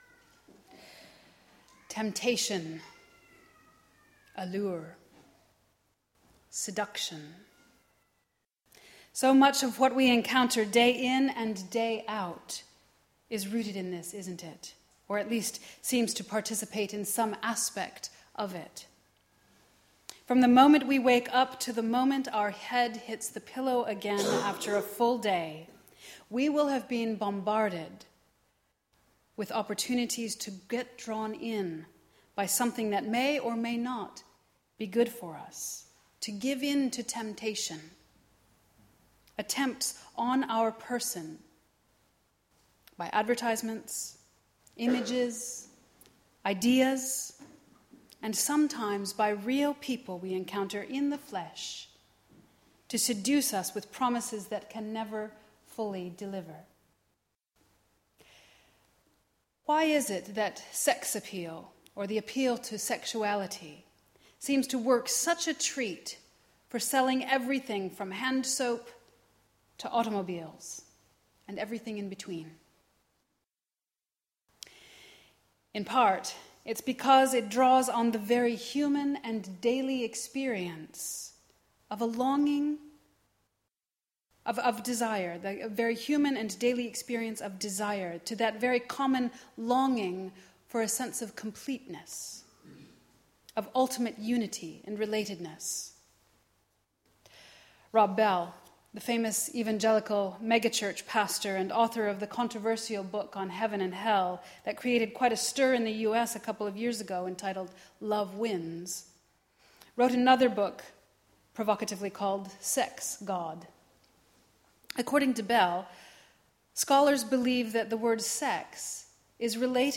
Creation & Human Sexuality Creation and Human Sexuality: What the Bible Says (and Doesn’t Say) on, Human Sexuality, and the Fall 27th January 2013 The first in a series of sermons and part of an ongoing debate on this topic.
1 Corinthians 12:12-31 Service: Sunday Morning